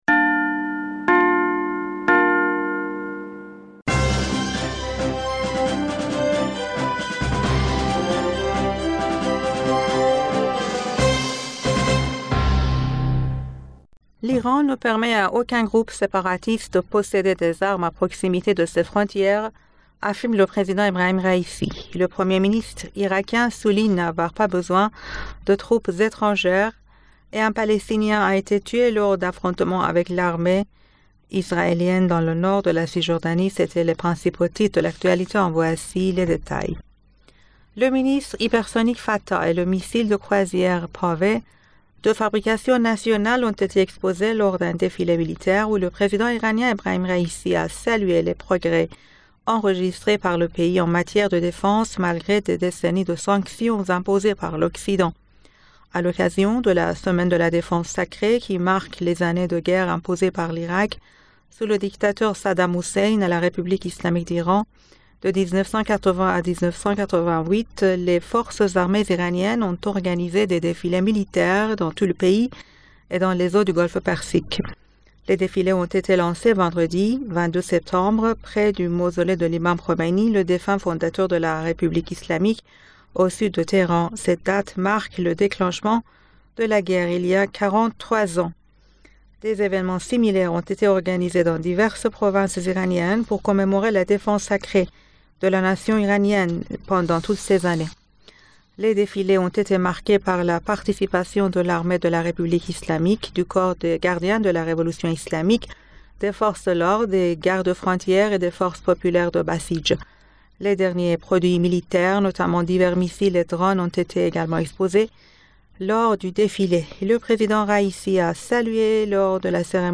Bulletin d'information du 22 Septembre 2023